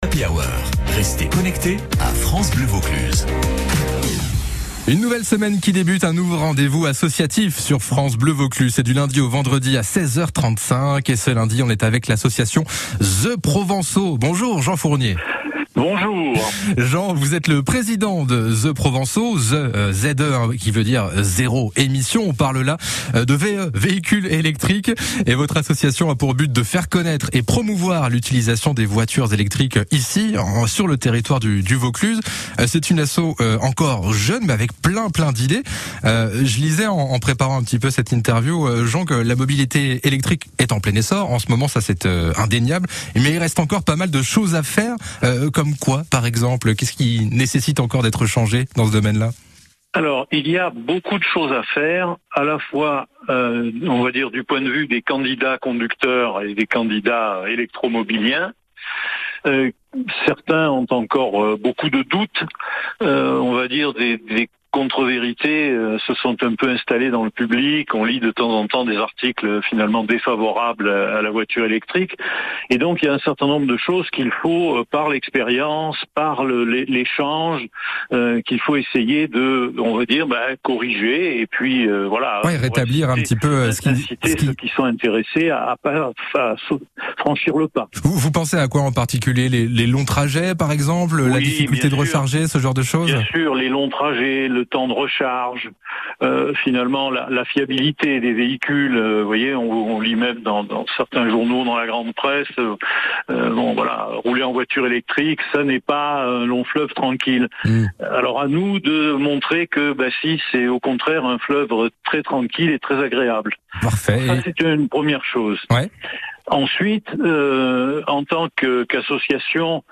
Interview France Bleu Vaucluse